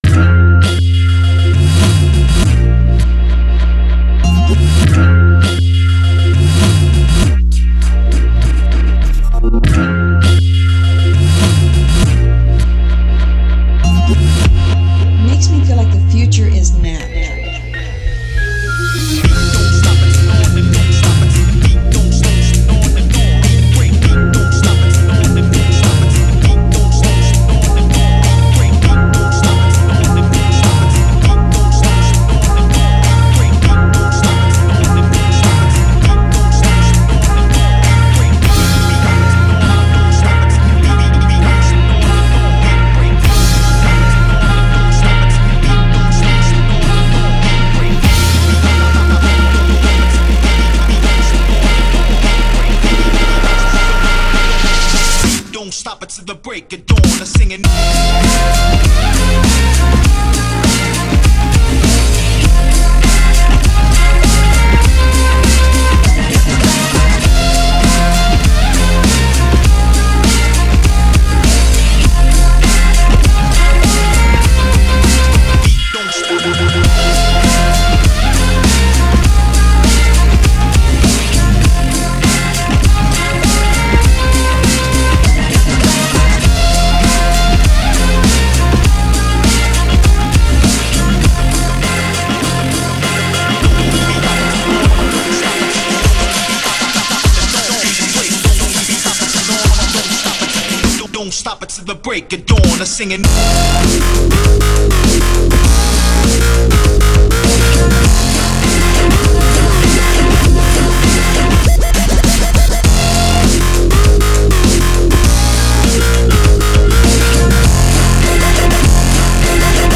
meandering saxophone and dubsteppy drops
dirty future funk
electro-funk bells and whistles
pure library filth